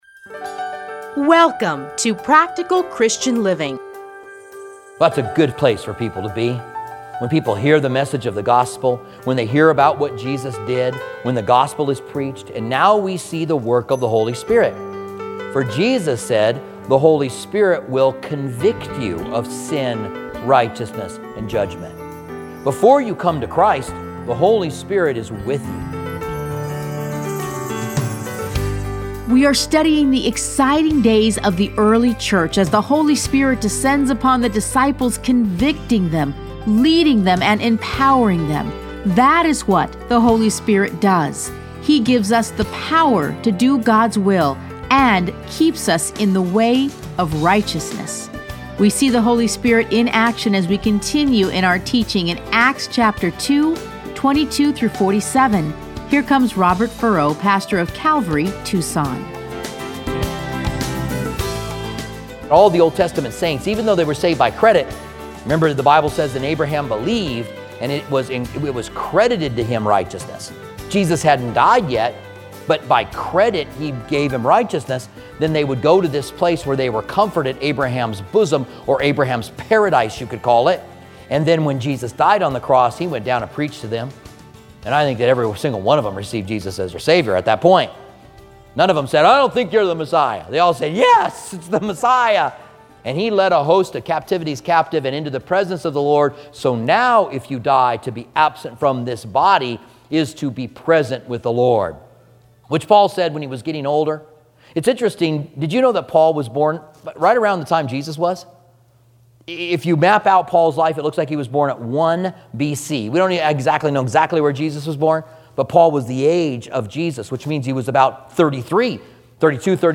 Listen to a teaching from Acts 2:22-47.